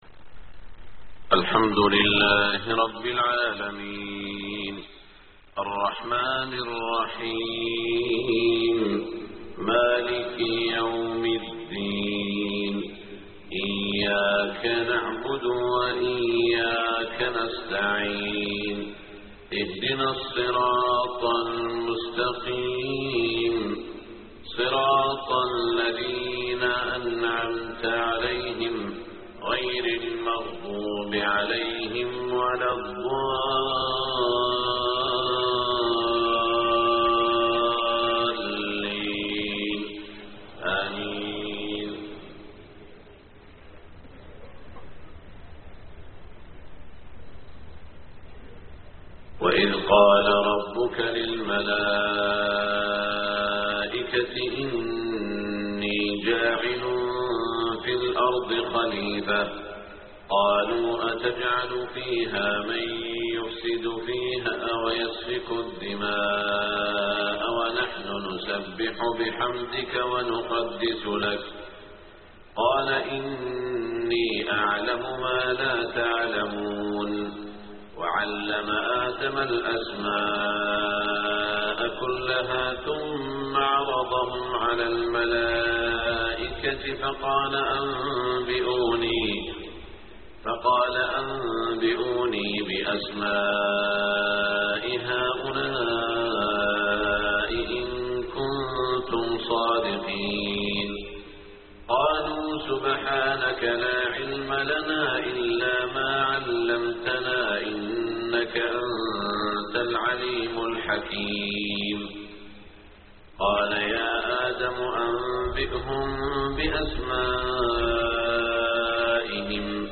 صلاة الفجر 16 ذو القعدة 1427هـ من سورة البقرة > 1427 🕋 > الفروض - تلاوات الحرمين